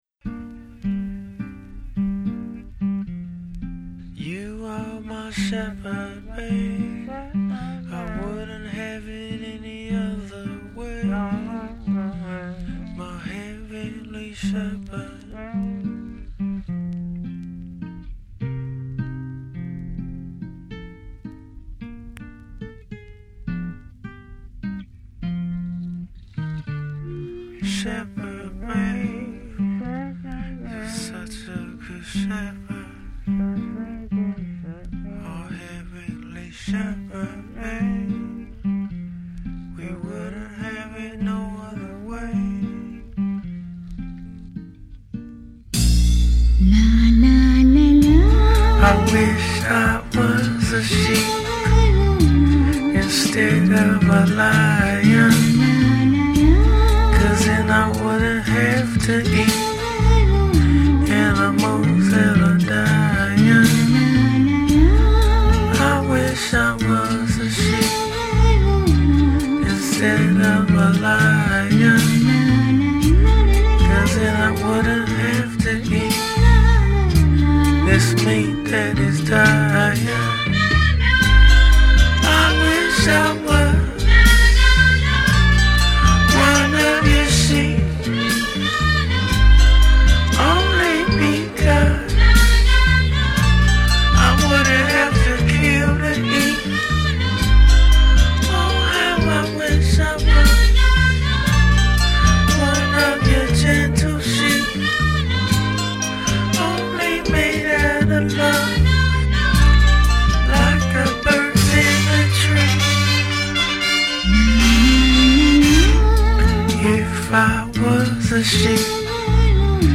expertly fits the dreamy musings